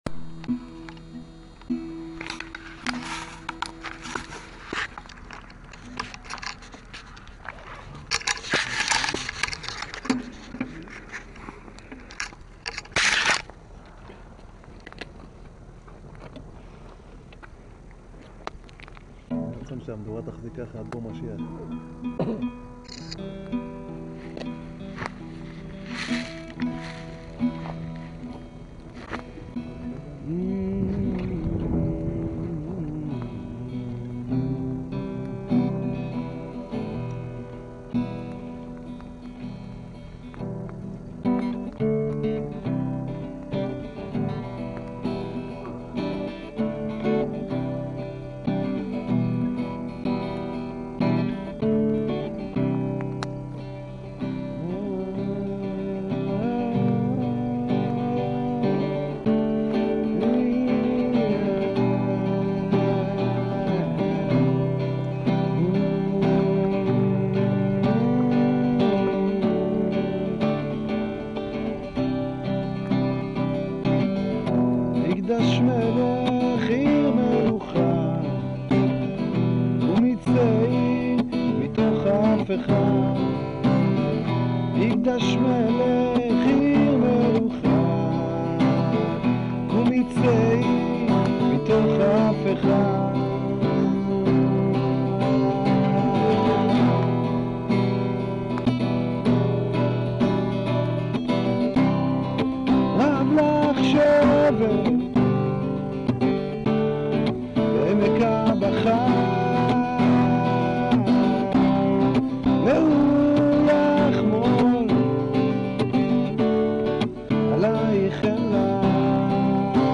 בבית חגלה מול הר נבו
ניגון ותפילה